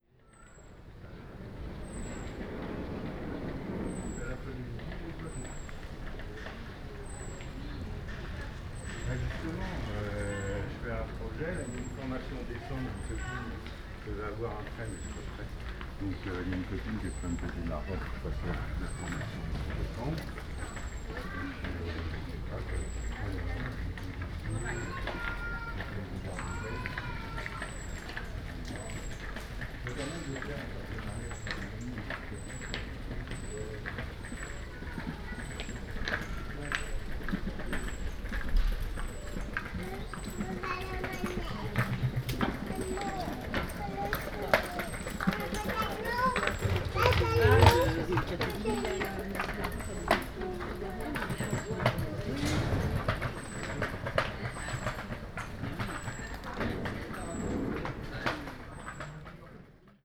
Une voie privée calme, entourée de maisons dont on n’ose pas imaginer les prix ou les loyers.
Appuyé contre un mur, les micros dans les oreilles, faire semblant d’être absorbé dans son smartphone, pendant que les passant passent. Son binaural, au casque c’est mieux.
Paris, 26/10/2022